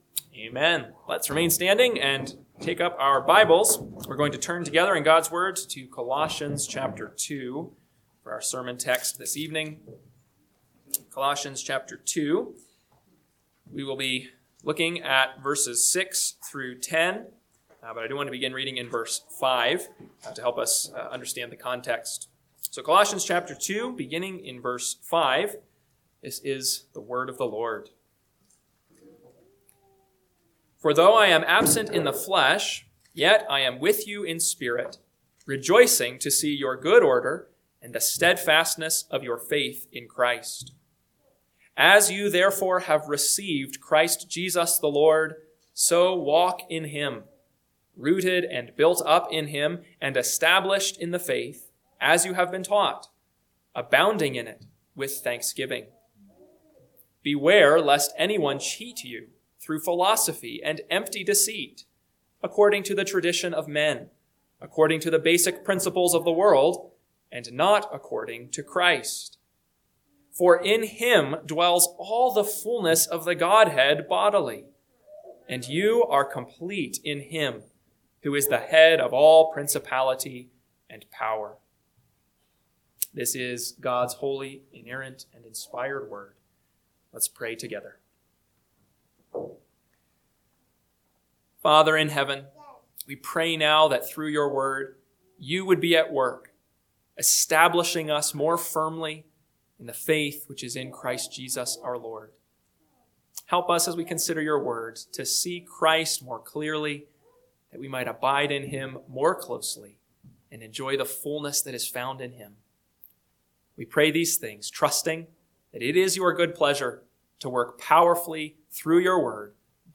PM Sermon – 2/22/2026 – Colossians 2:6-10 – Northwoods Sermons